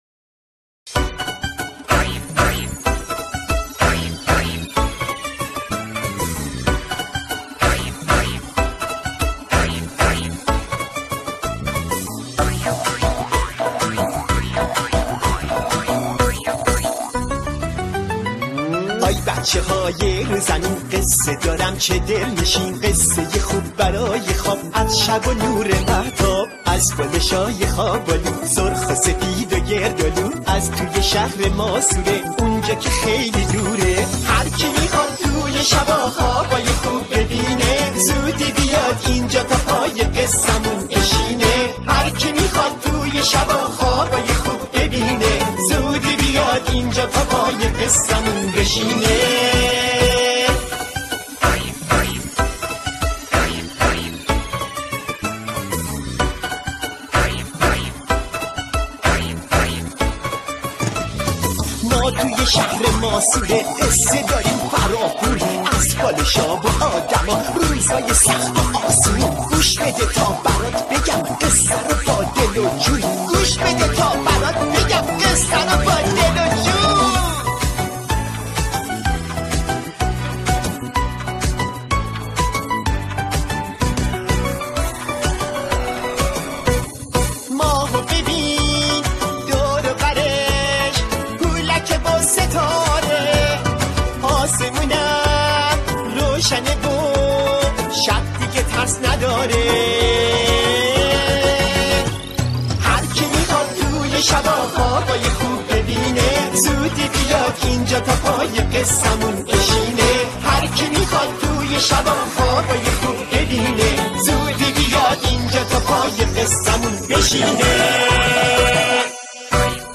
آهنگ تیتراژ